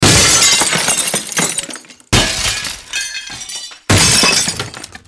Звуки окна, стекла
Громкий треск разбитого стекла или зеркала